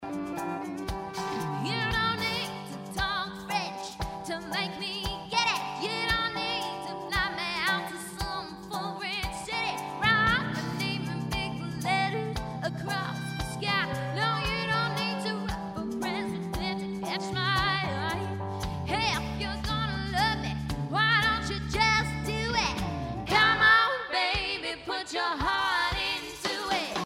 C  O  U  N  T  R  Y     C  O  V  E  R  S